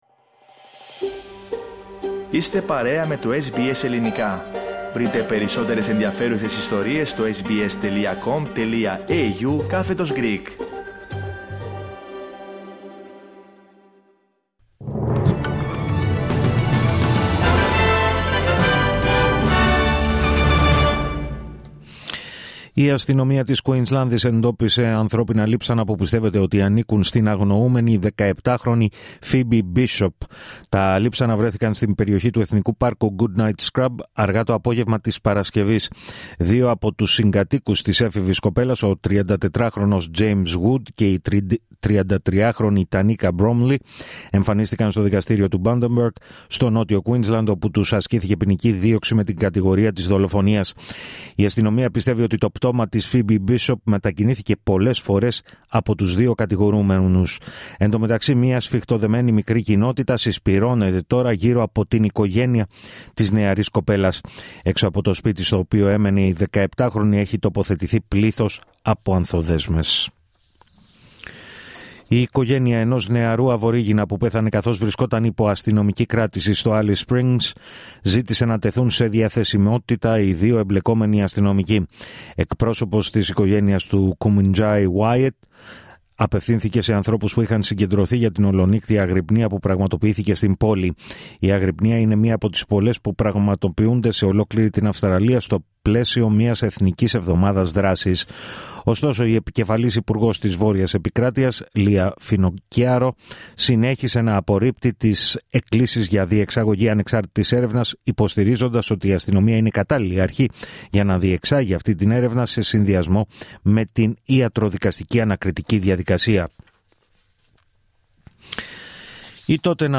Δελτίο Ειδήσεων Σάββατο 7 Ιουνίου 2025